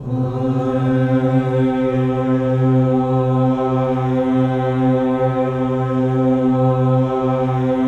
VOWEL MV08-R.wav